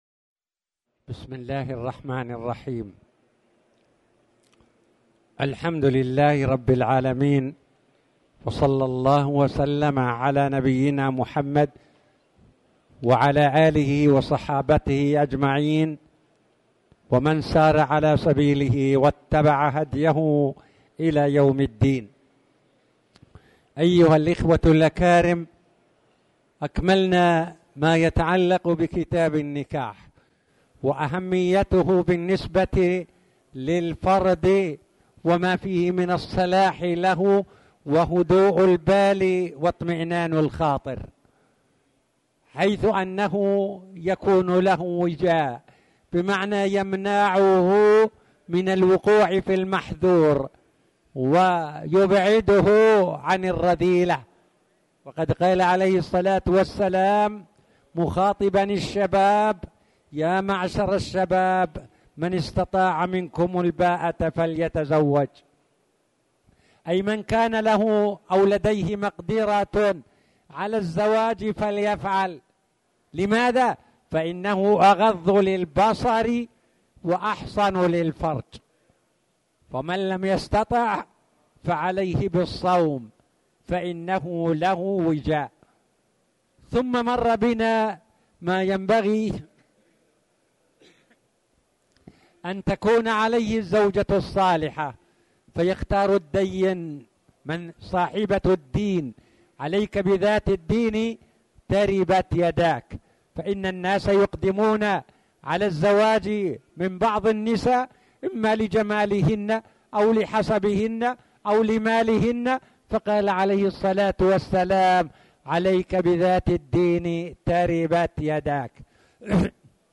تاريخ النشر ٢٩ صفر ١٤٣٨ هـ المكان: المسجد الحرام الشيخ